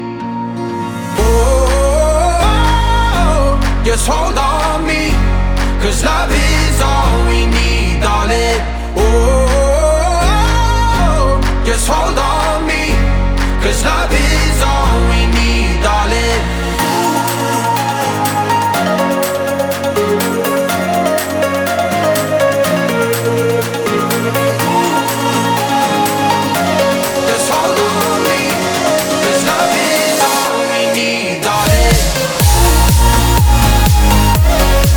Жанр: Поп / Электроника